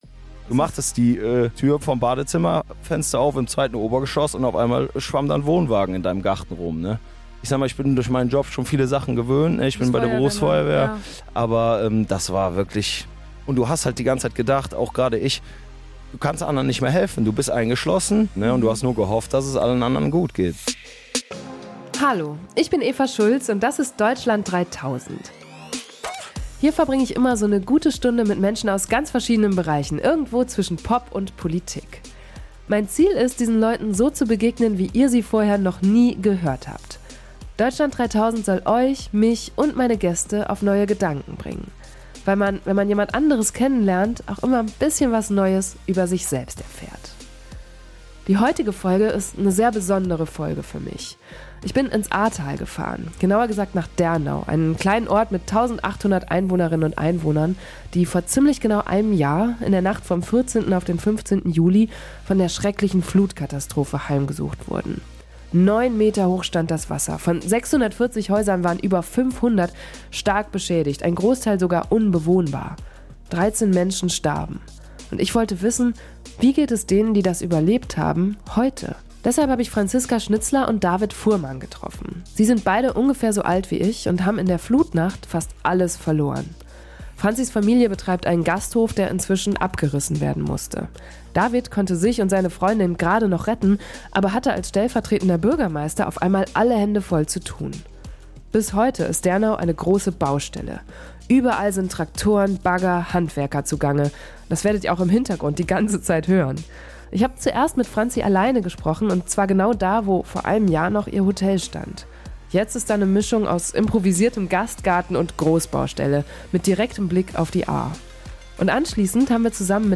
Überall sind Traktoren, Bagger, Handwerker zugange – das werdet ihr auch im Hintergrund die ganze Zeit hören.
Jetzt ist da eine Mischung aus improvisiertem Gastgarten und Großbaustelle, mit direktem Blick auf die Ahr.